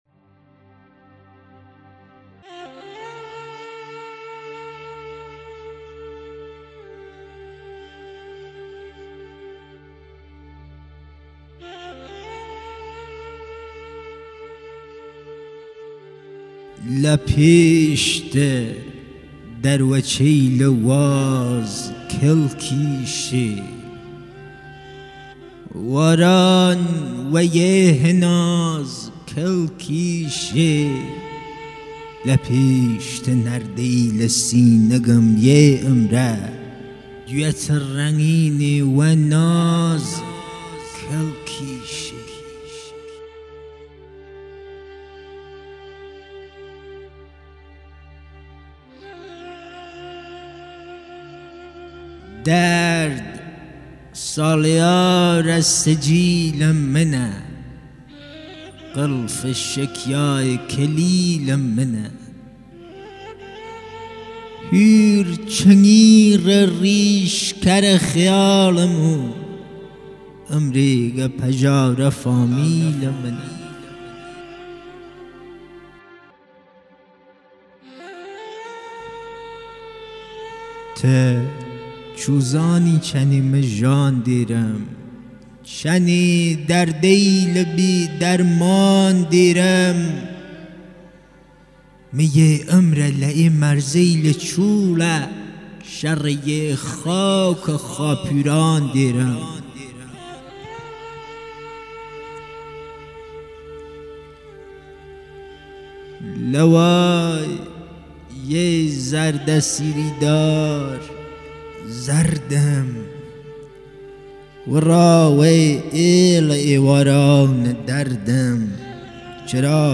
آلبوم صوتی مجموعه شعر کردی کلهری
با نوای زیبا و دلربای اساتید صاحب فن "کمانچه نوازی
پیانو نوازی اساتید زنده یاد بابک بیات و انوشیروان روحانی
قره نی نوازی